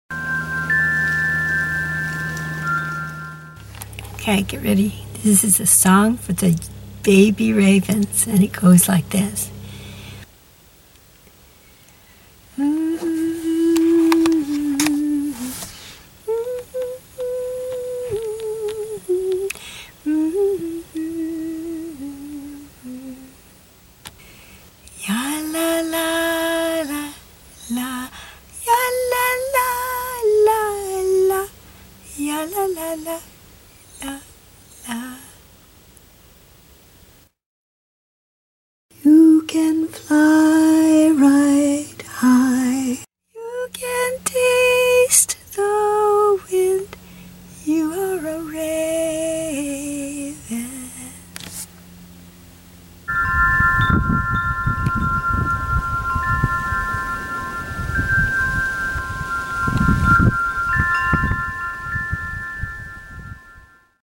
Location: Santa Monica Mountains, CA